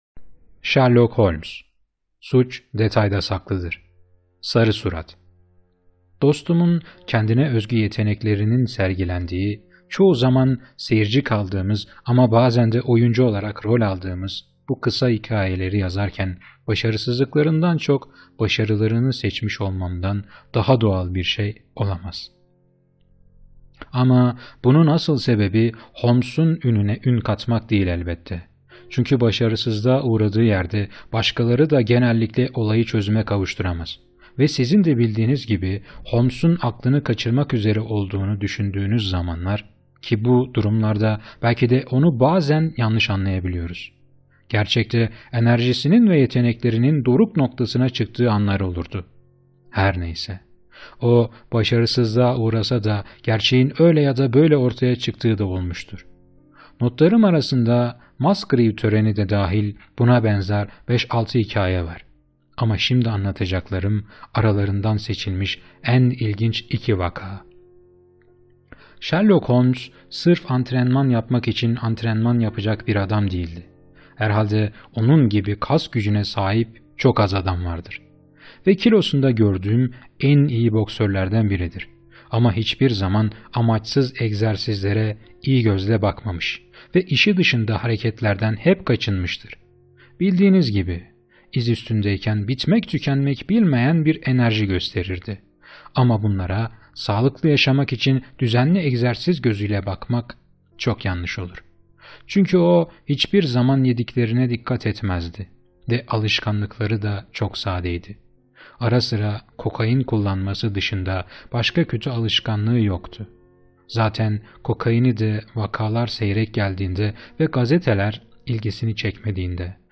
Sherlock Holmes - Sarı Surat - (SESLİ KİTAP) - Kitap Okumaları
Sherlock Holmes’un Gizem Dolu Dünyasına Sesli Bir Yolculuk